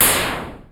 ihob/Assets/Extensions/RetroGamesSoundFX/Shoot/Shoot25.wav at master
Shoot25.wav